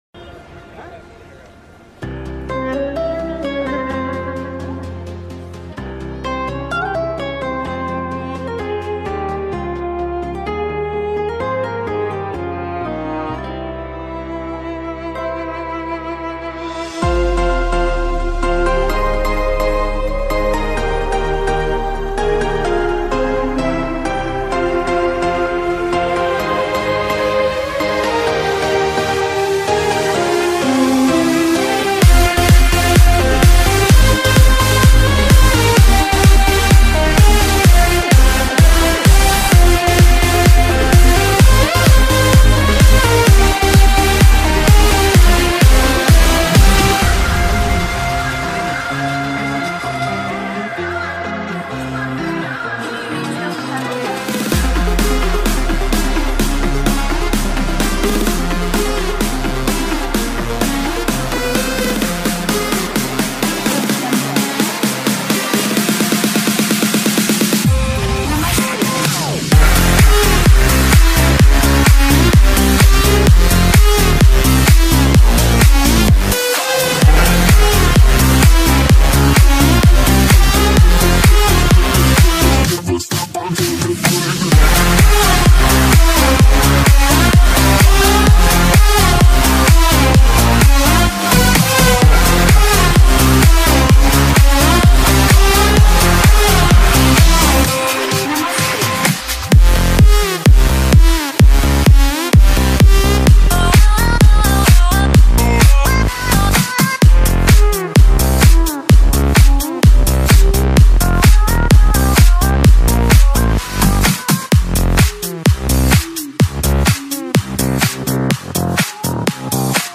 BPM32-128